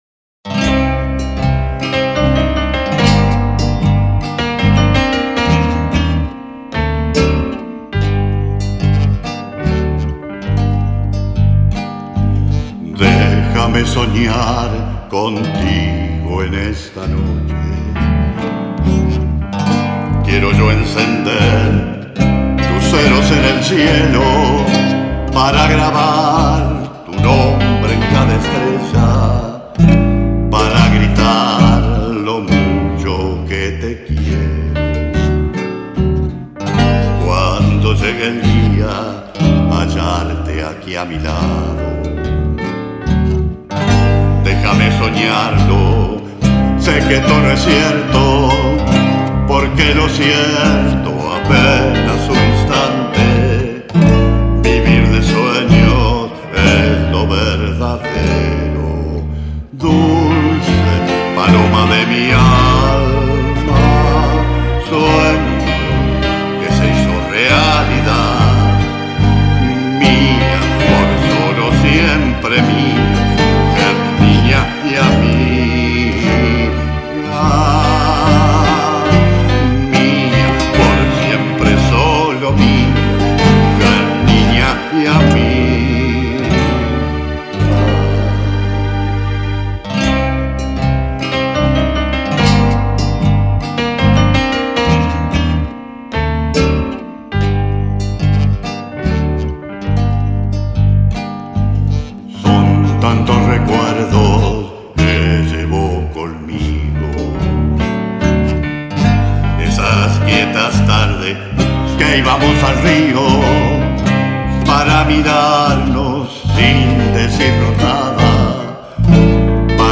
zamba
Interprete canto y guitarra